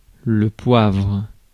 Prononciation
PrononciationFrance:
• IPA: [lø pwavʁ]